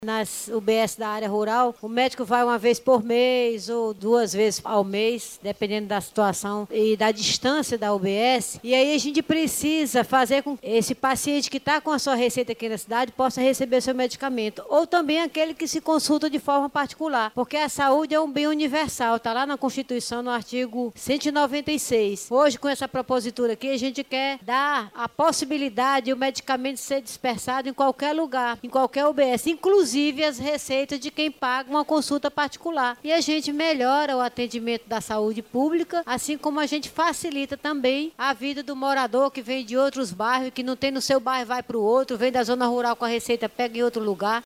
A autora do Projeto de Lei, a vereadora Professora Jacqueline, do União Brasil, destacou que a proposta visa facilitar a vida dos usuários do Sistema Único de Saúde – SUS, principalmente, daqueles que moram em localidades distantes.